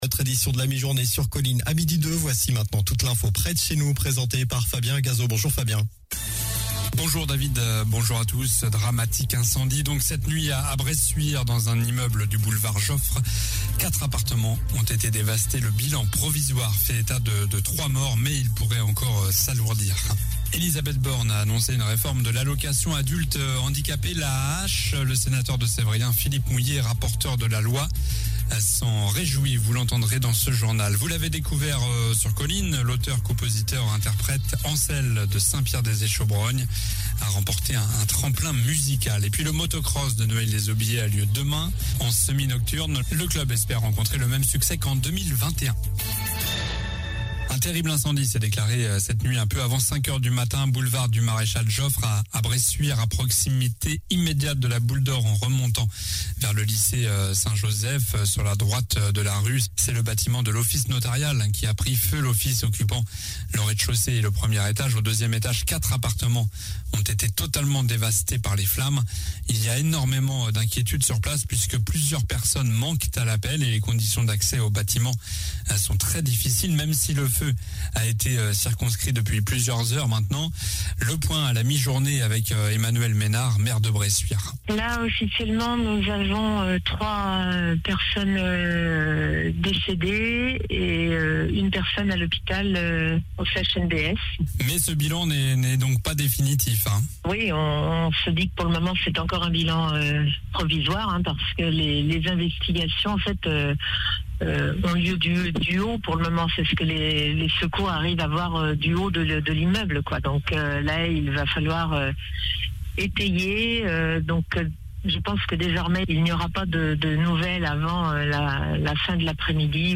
Journal du vendredi 08 juillet (midi)